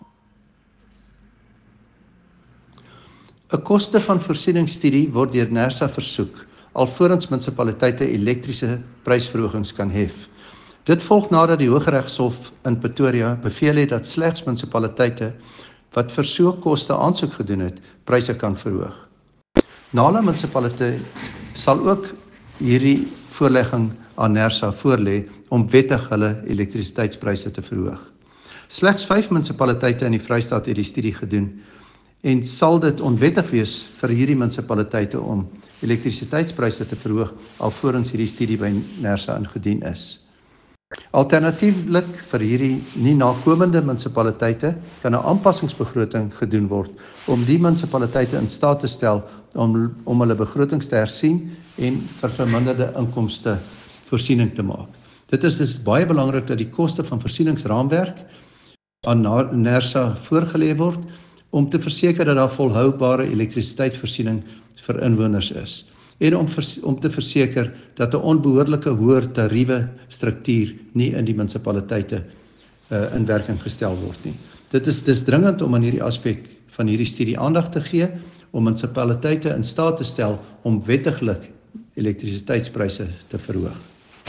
Afrikaans soundbites by Cllr David Ross and